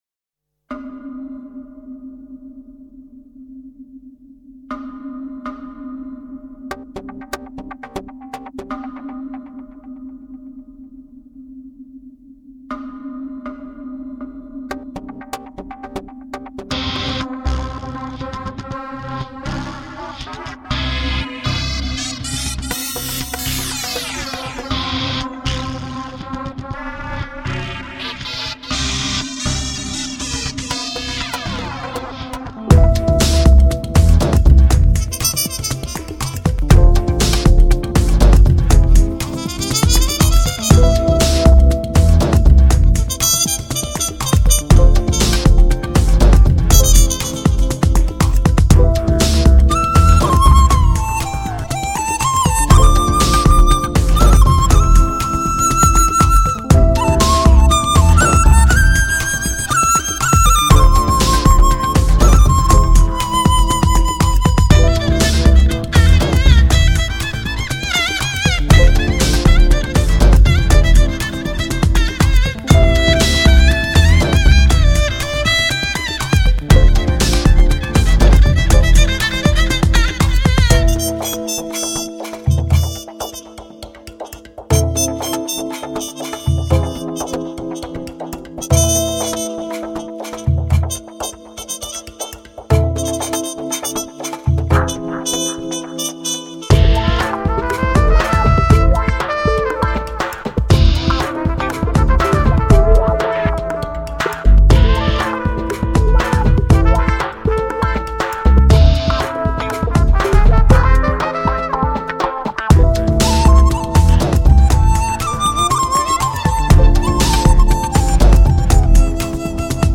HIFI音乐
唯美抒情的弦乐旋律 叹为观止的低频动态 写意生动的国乐名器
中央电视台480平米录音棚